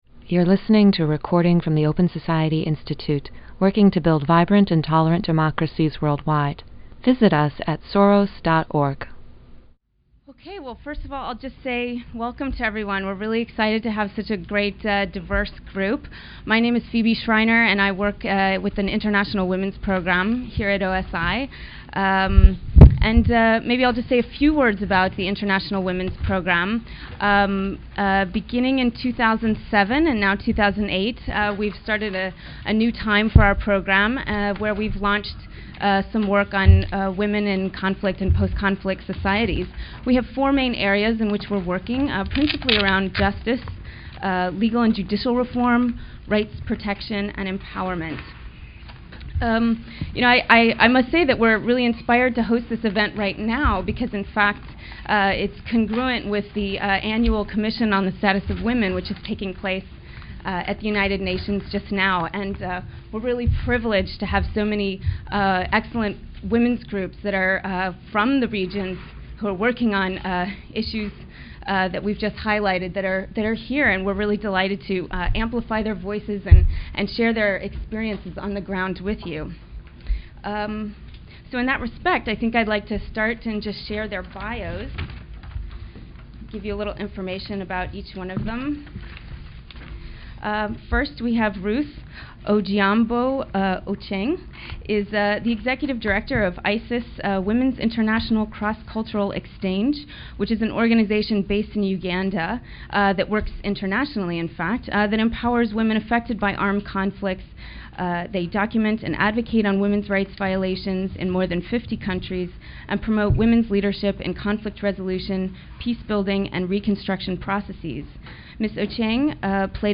At this OSI event, panelists discussed their cutting-edge work addressing violence against women in conflict and post-conflict zones, and where they see governments failing.
Aryeh Neier, President of the Open Society Institute, moderated.